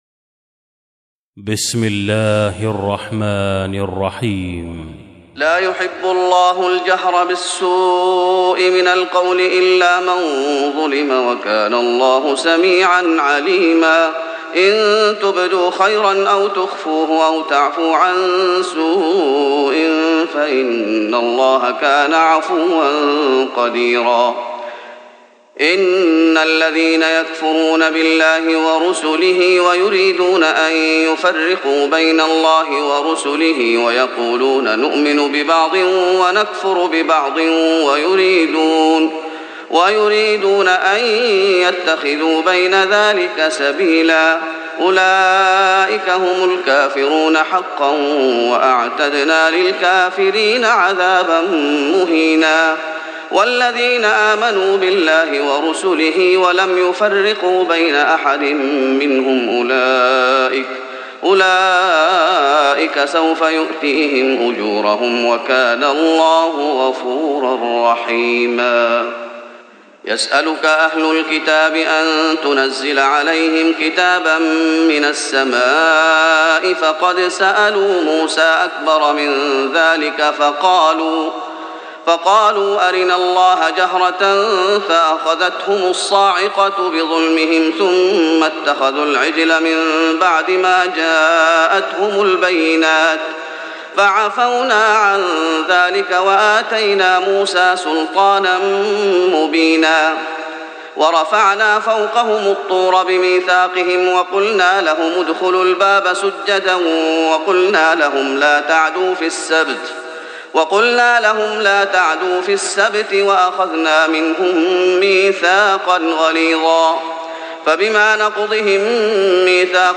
تراويح رمضان 1415هـ من سورة النساء (148-176) Taraweeh Ramadan 1415H from Surah An-Nisaa > تراويح الشيخ محمد أيوب بالنبوي 1415 🕌 > التراويح - تلاوات الحرمين